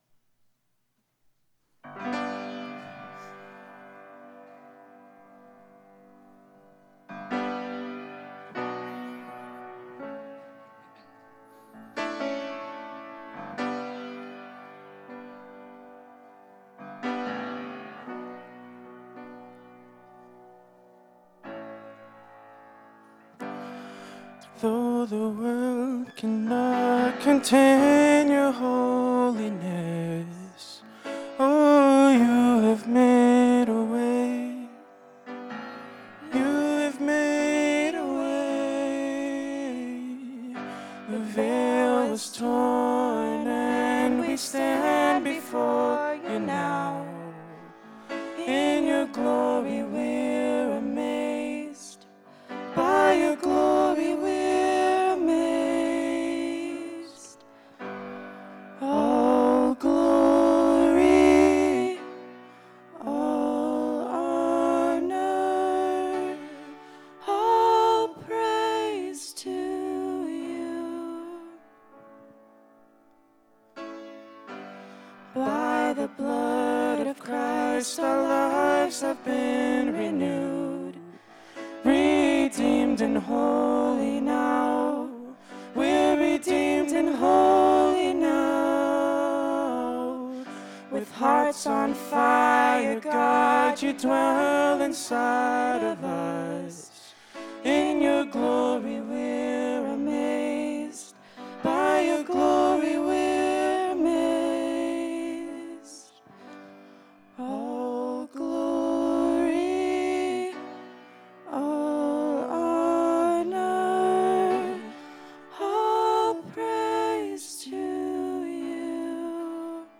Music Forum